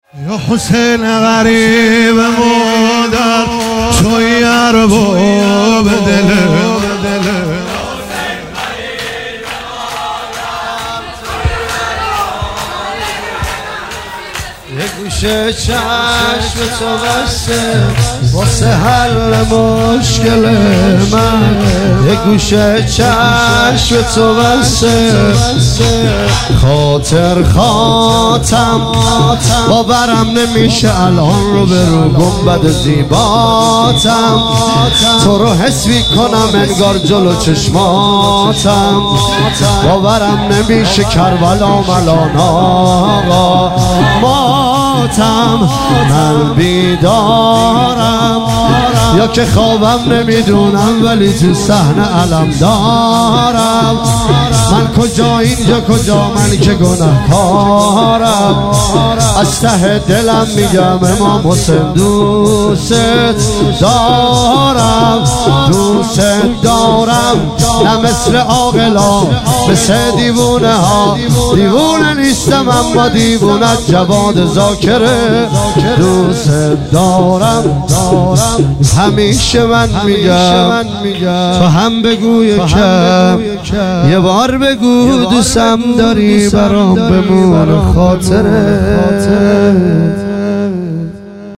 عنوان شب سیزدهم صفر ۱۳۹۹
شور